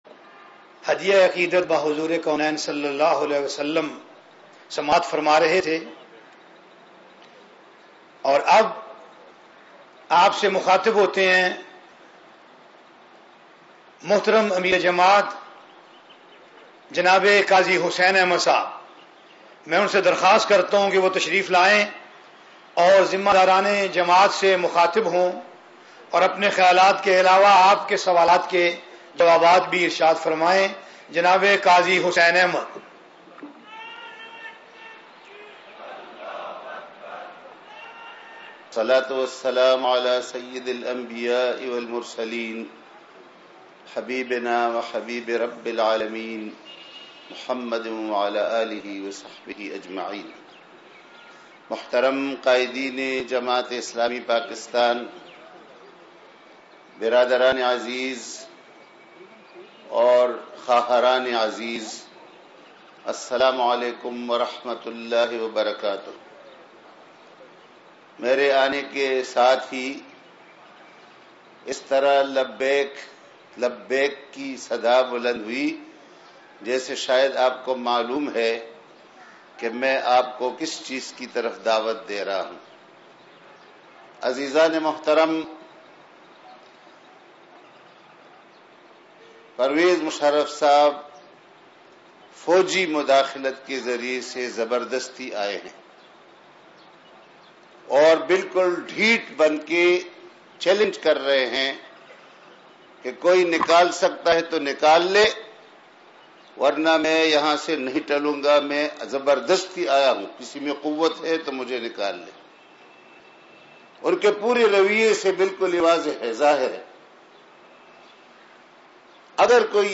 Khitab
4146_Khitab_Qazi-Hussain-Ahmed.mp3